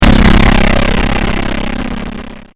爆破系？
bomb1.mp3